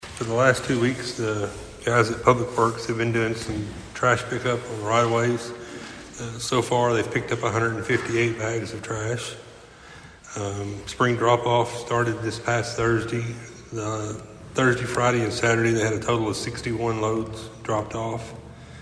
At Monday night’s meeting, the Princeton City Council received updates on local issues, including trash and limb pickups and a roof collapse at a downtown building.